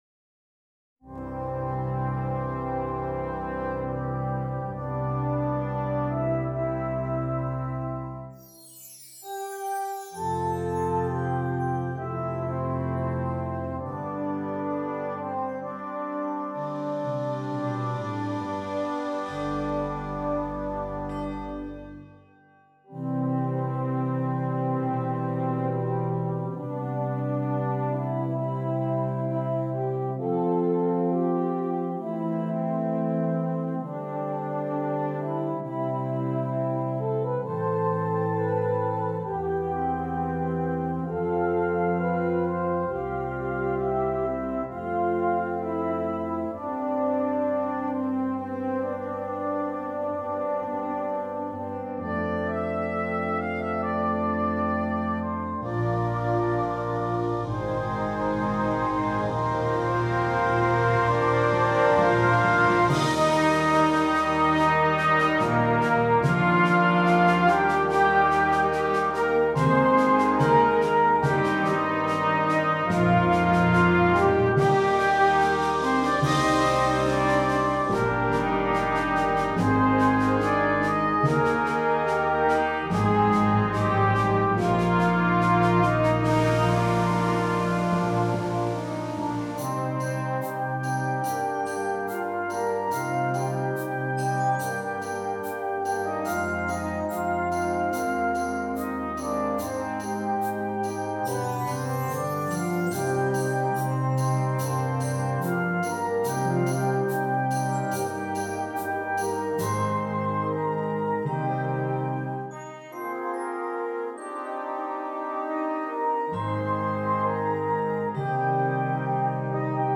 Brass Choir
Sounding very patriotic in nature